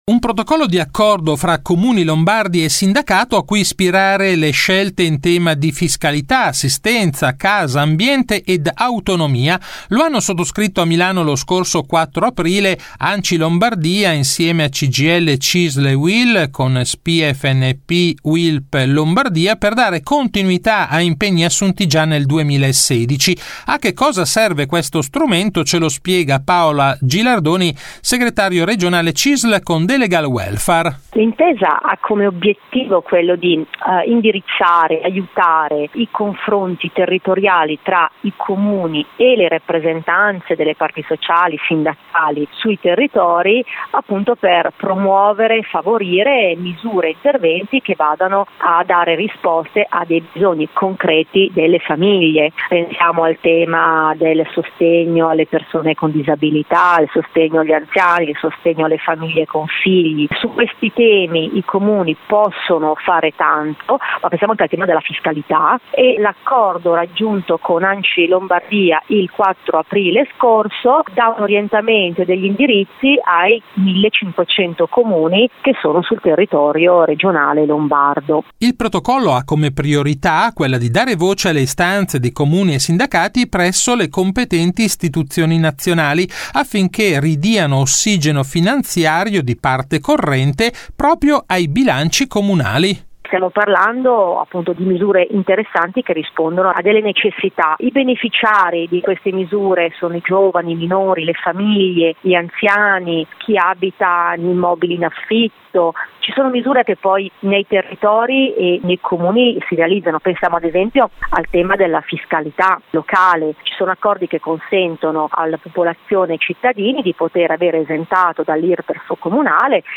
Questa settimana intervista